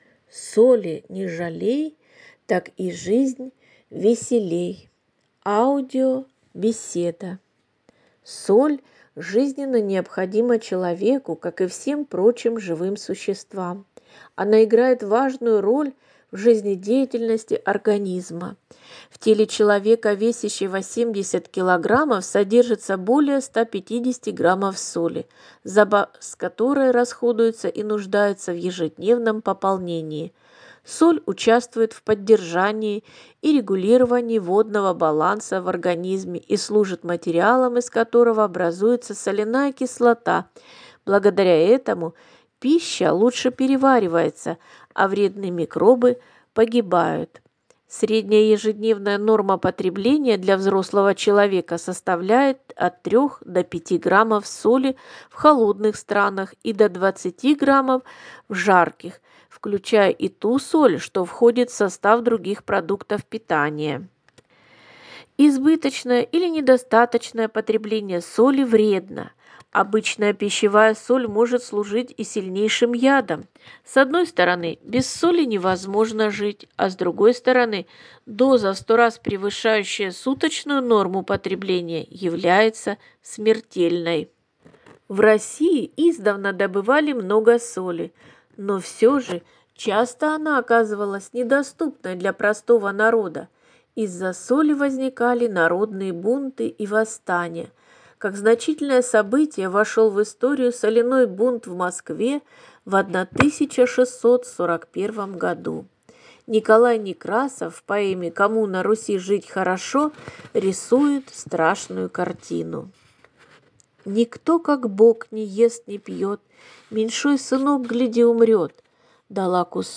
О необходимости соли, о том какую важную роль она играет в жизнедеятельности человеческого организма, о значении ее в жизни человека, звучит аудиобеседа «